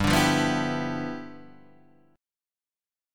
G13 chord